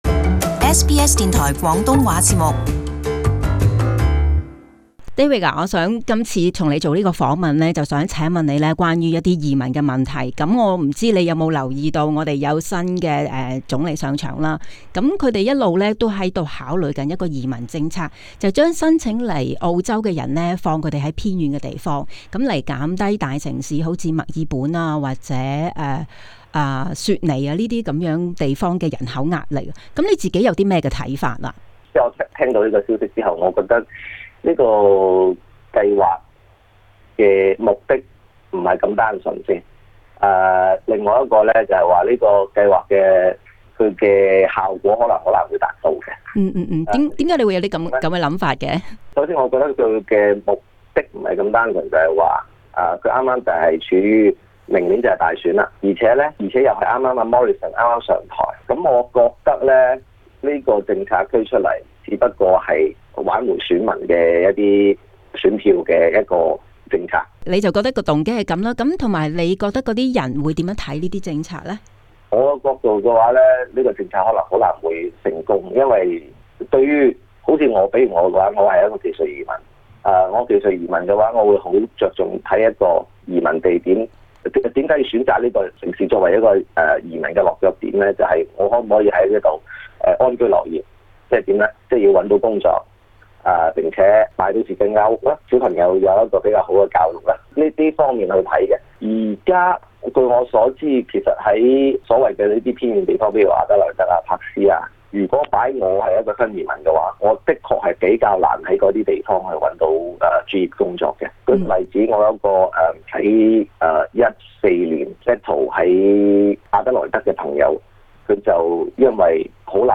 【社區專訪】政府的郷郊移民政策是否可行